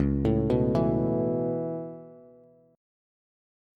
Dbm7#5 Chord
Listen to Dbm7#5 strummed